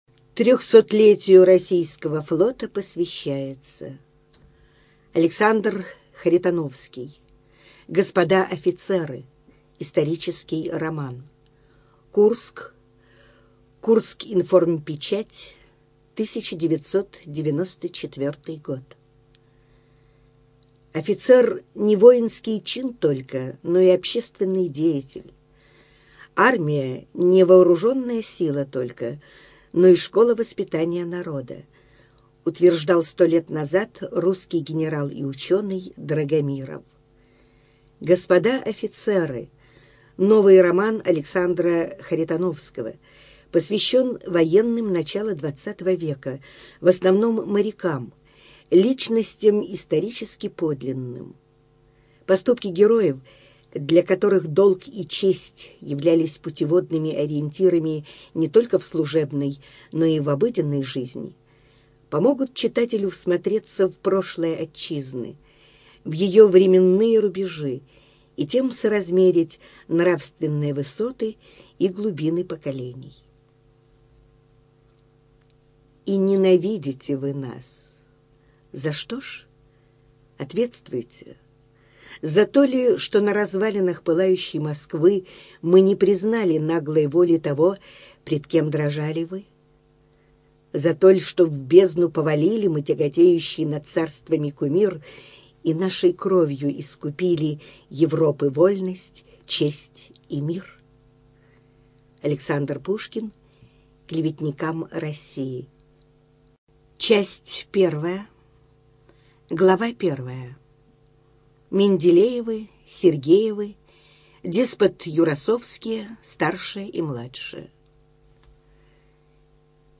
Студия звукозаписиКурская областная библиотека для слепых